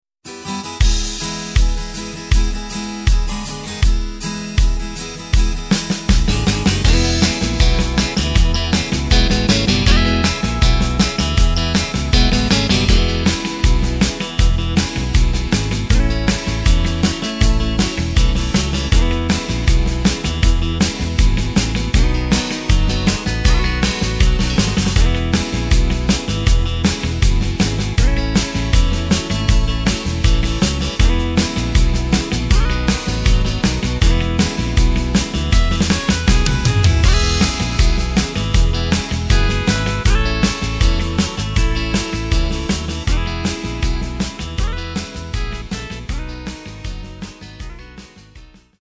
Takt:          4/4
Tempo:         159.00
Tonart:            E
Country-Beat aus dem Jahr 2021!
Playback mp3 Demo